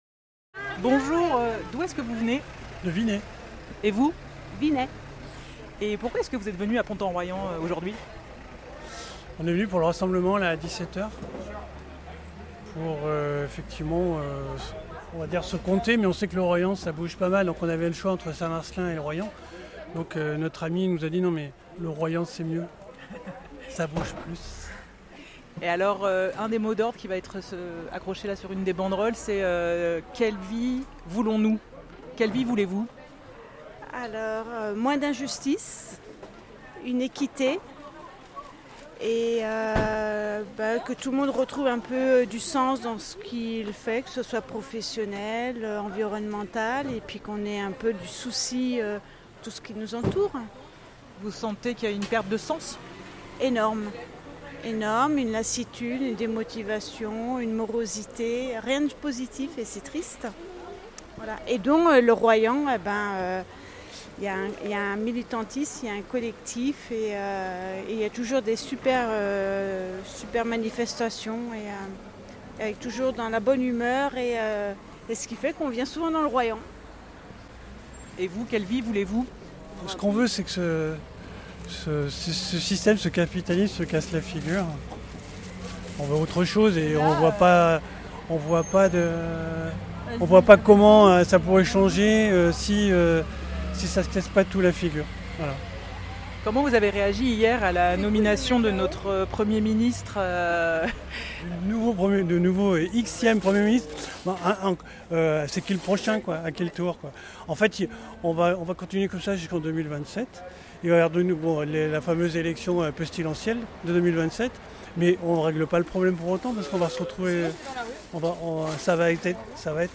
Micro-trottoir réalisé le mercredi 10 septembre 2025 à Pont en Royans, où 200 personnes étaient présentes entre 17h et 20h pour réaliser un barrage filtrant au pont Picard.